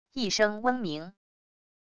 一声嗡鸣wav音频